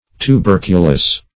Tuberculous \Tu*ber"cu*lous\, a. (Med.)